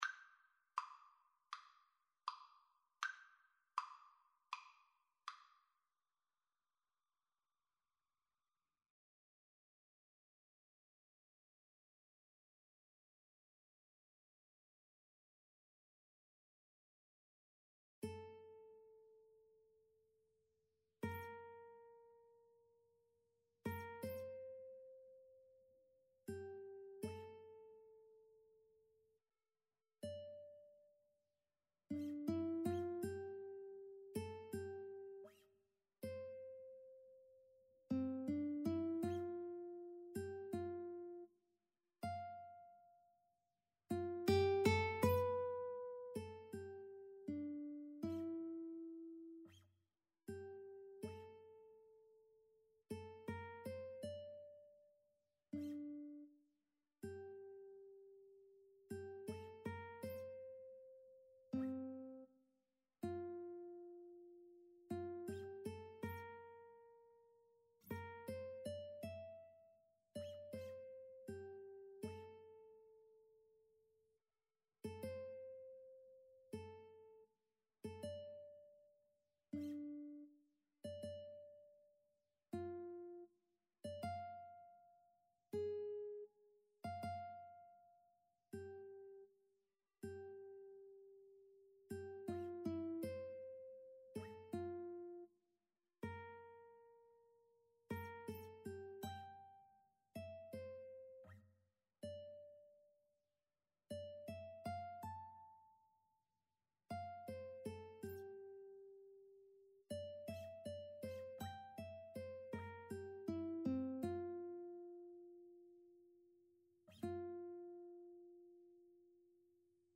• Unlimited playalong tracks
Arrangement for Guitar Duet
Classical (View more Classical Guitar Duet Music)